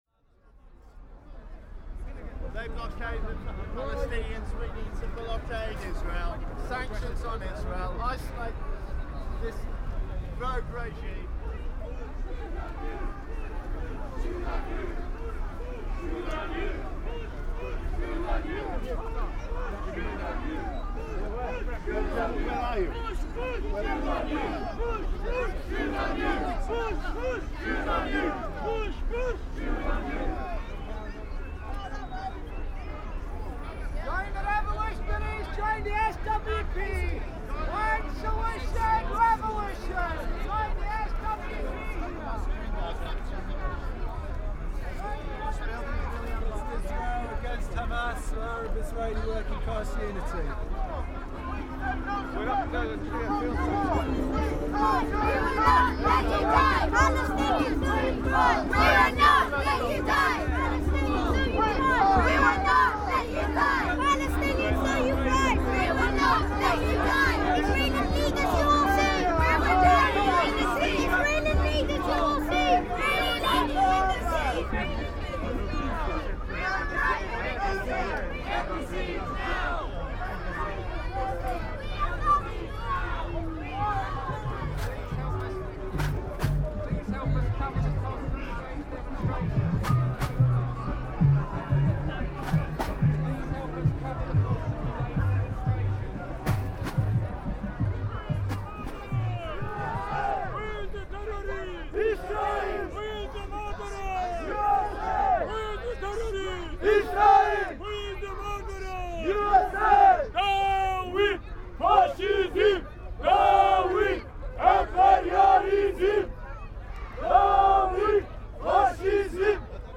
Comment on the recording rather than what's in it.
Gaza demo Hyde Park